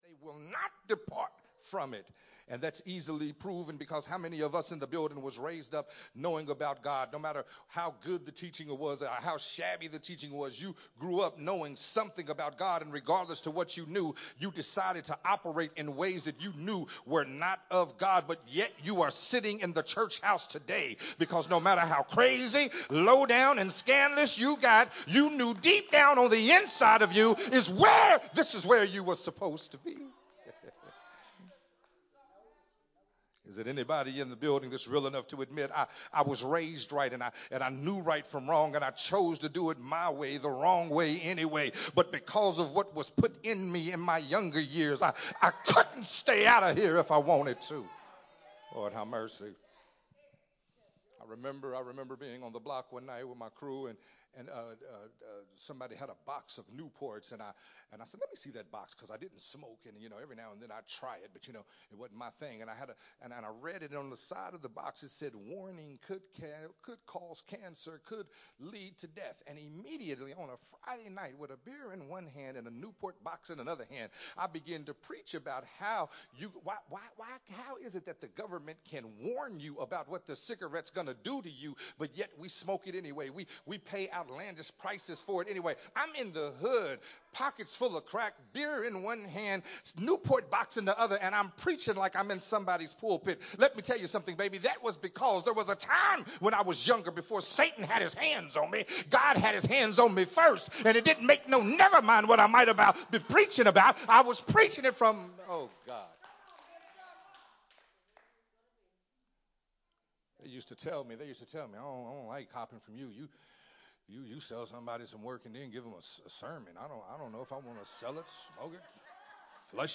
Sunday Morning Worship sermon
recorded at Unity Worship Center on March 5th, 2023.